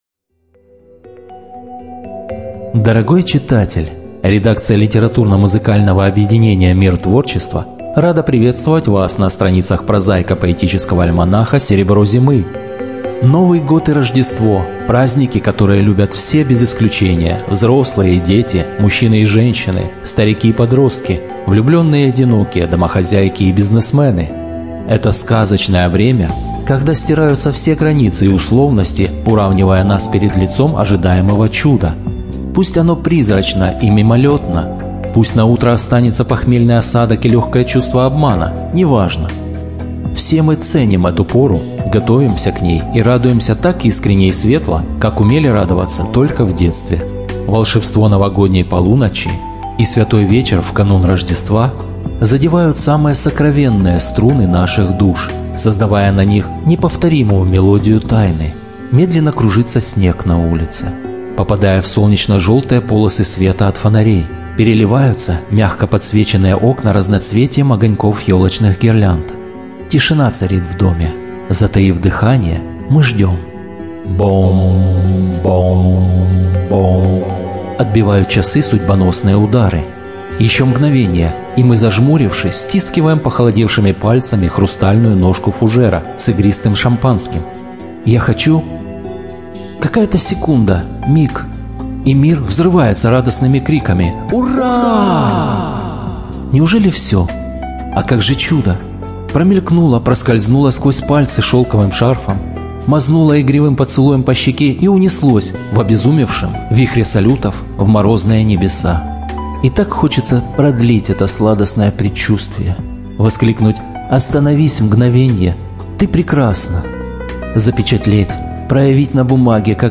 Студия звукозаписиМТ-Records